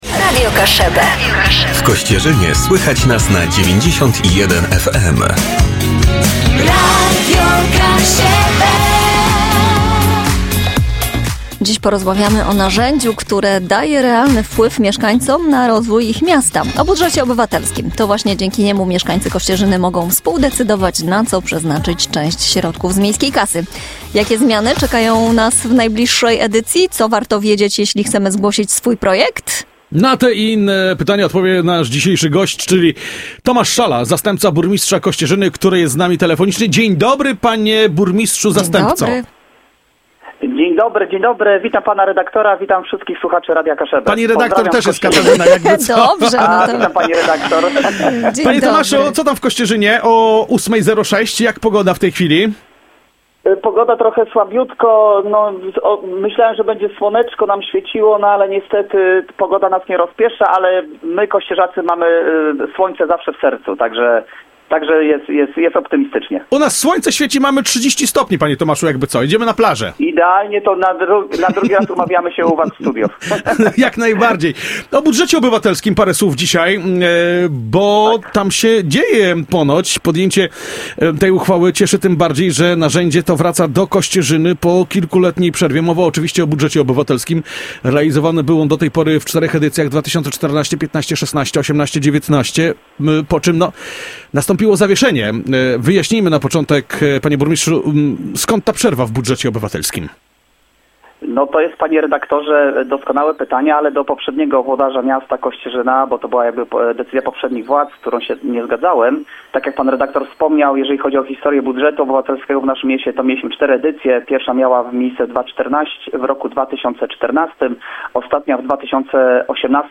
Po kilkuletniej przerwie do Kościerzyny wraca budżet obywatelski! Zastępca burmistrza Tomasz Szala w rozmowie z Radiem Kaszëbë wyjaśnił przyczyny zawieszenia poprzednich edycji, przedstawił główne zmiany w zasadach oraz zachęcił mieszkańców do aktywnego udziału w nowej odsłonie inicjatywy.
Tomasz-Szala-zastepca-burmistrza-Koscierzyny.mp3